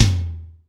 ROOM TOM3A.wav